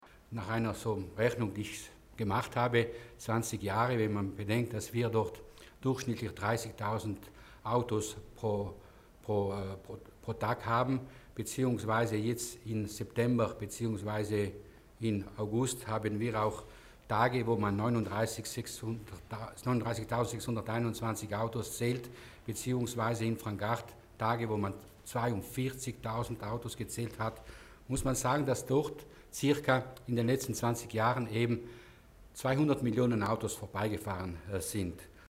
Landesrat Mussner erläutert das umfangreiche Sanierungskonzept für die MeBo